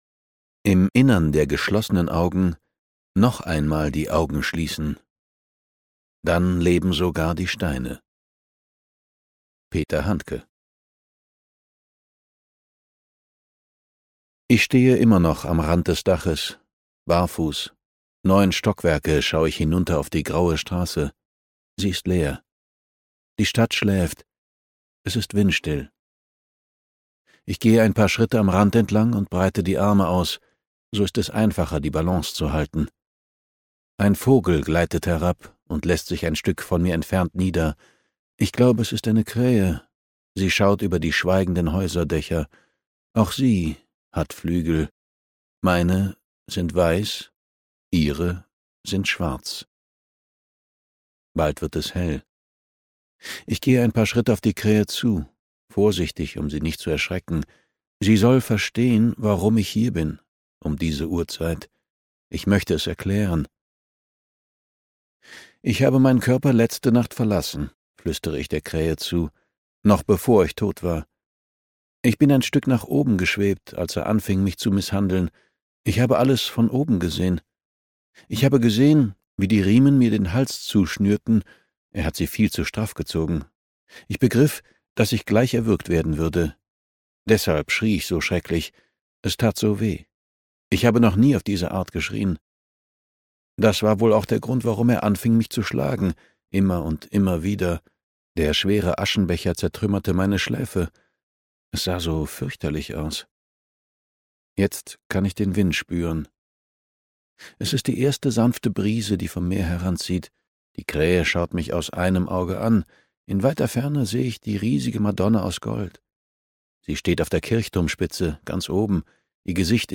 Die dritte Stimme (DE) audiokniha
Ukázka z knihy